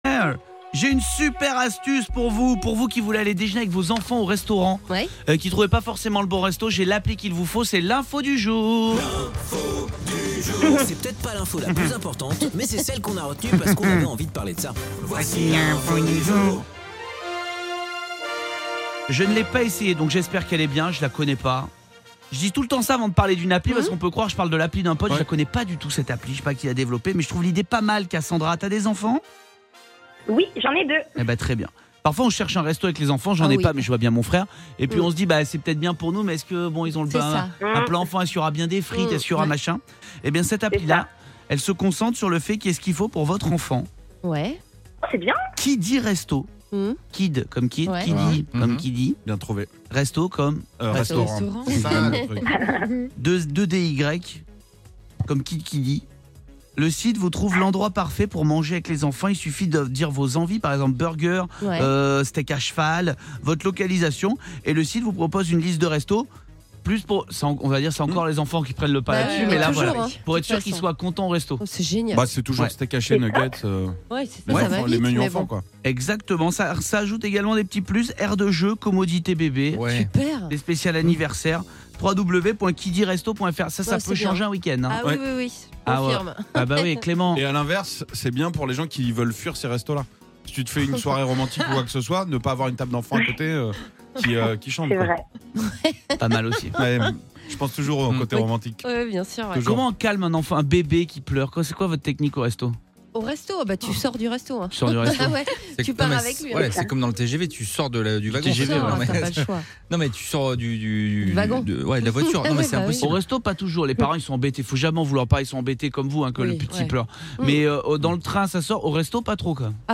Kiddy Resto présenté par Camille Combal sur Virgin Radio...